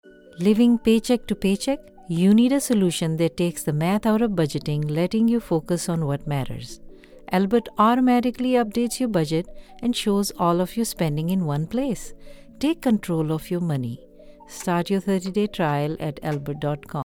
Bank App AD with music
The space is fully soundproofed to deliver clean, noise-free recordings.
Albert - Fintech Spot with music.mp3